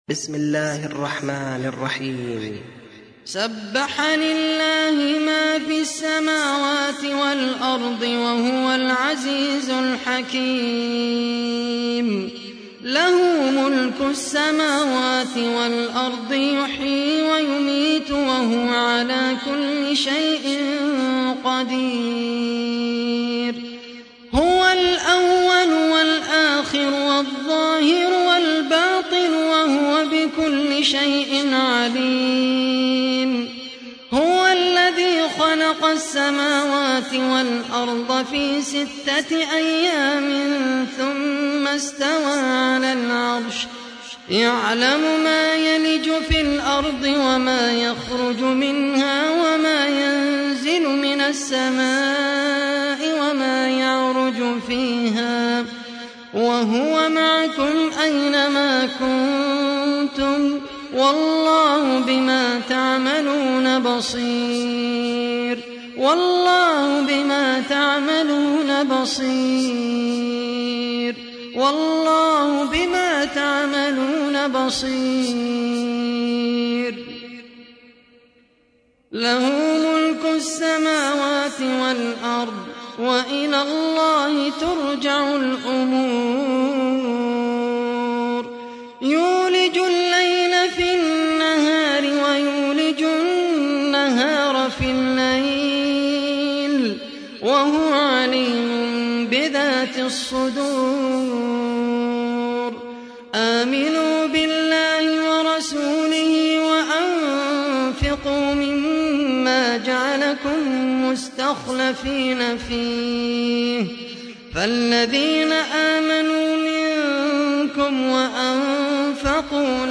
تحميل : 57. سورة الحديد / القارئ خالد القحطاني / القرآن الكريم / موقع يا حسين